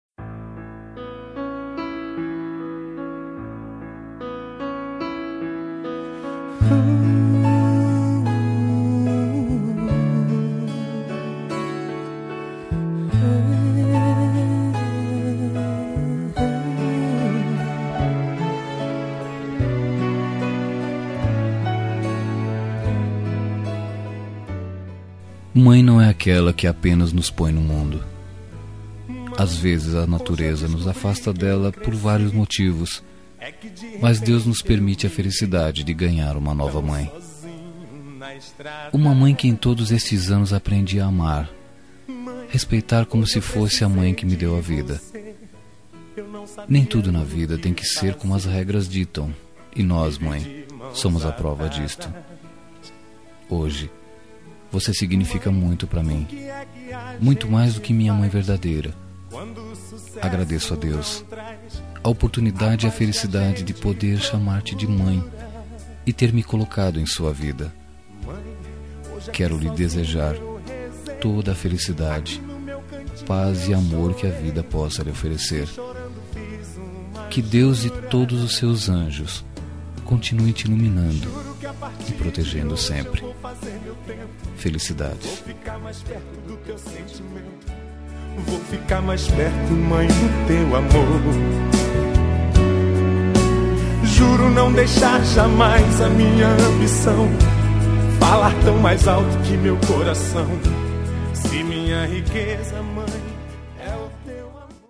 Dia das Mães – Adotiva – Voz Masculina – Cód: 6708